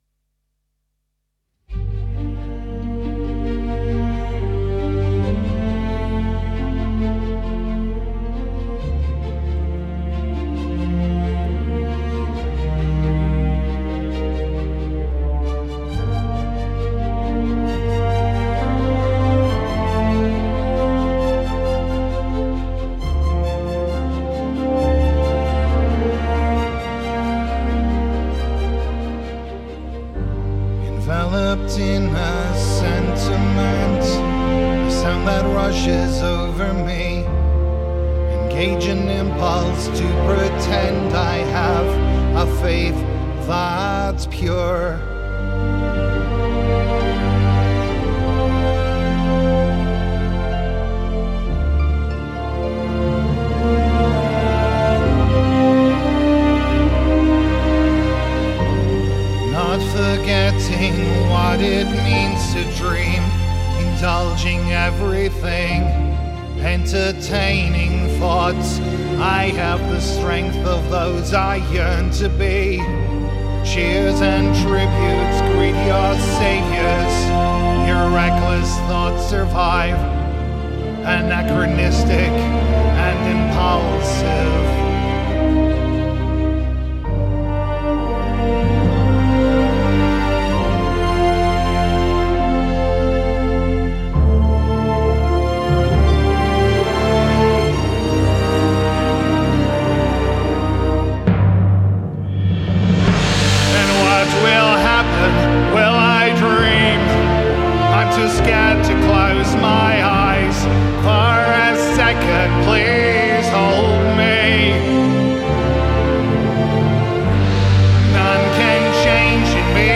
arranged purely for voice and orchestra
Genre: Classical, Electronic